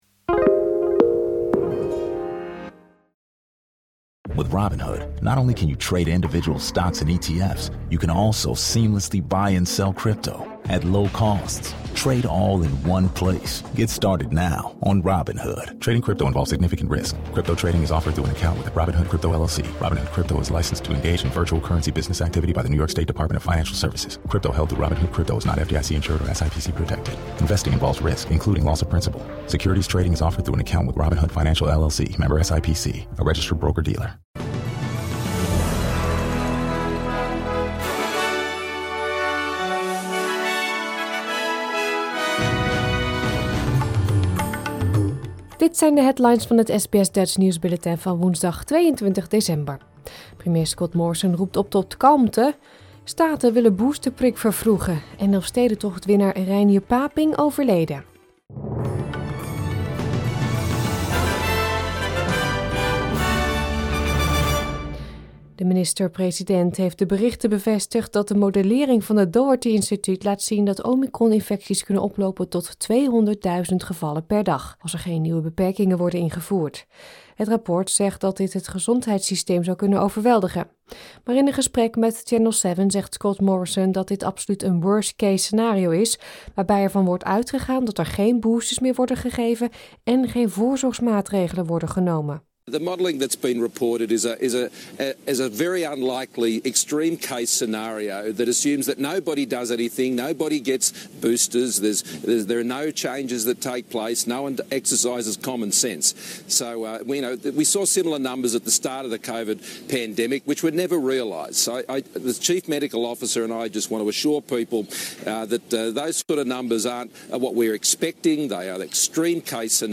Nederlands / Australisch SBS Dutch nieuwsbulletin van woensdag 22 december 2021